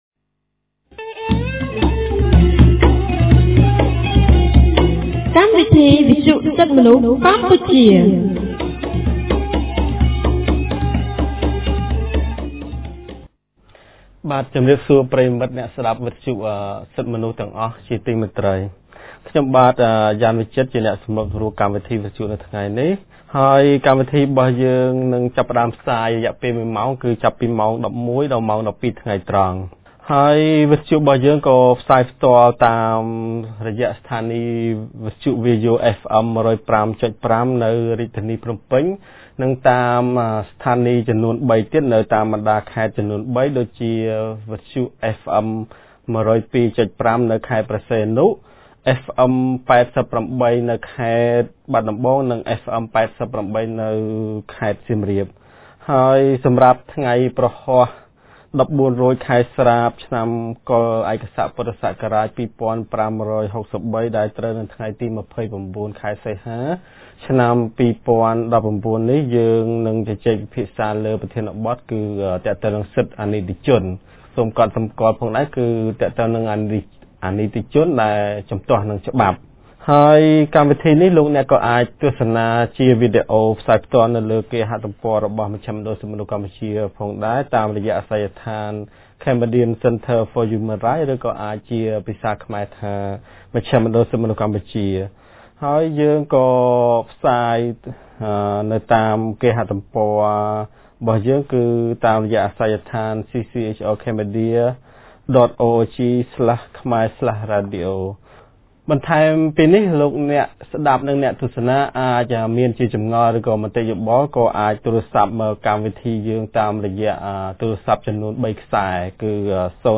On Thursday 29th August 2019, CCHR's Fair Trial Rights Project (FTRP) held a radio program with a topic "Right of Juveniles".